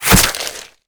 flesh2.ogg